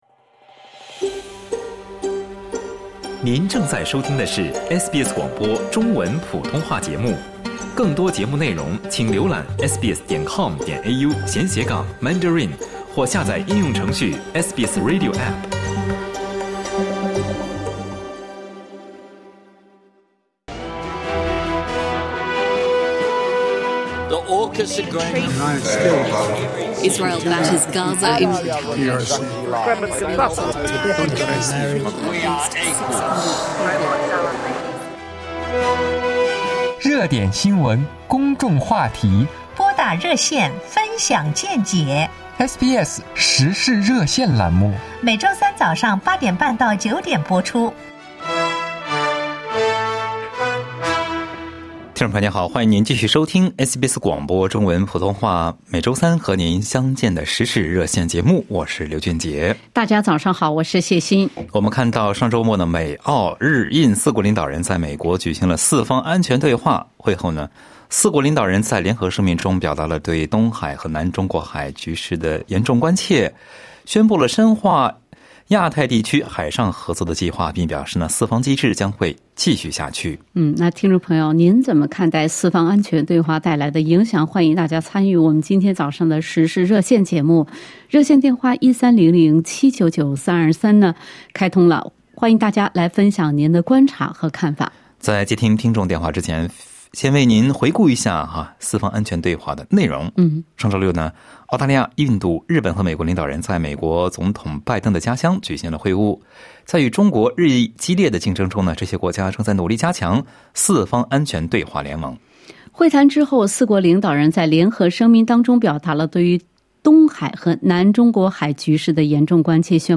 在本期《时事热线》节目中，听友们就四方安全会谈的影响表达了各自的看法。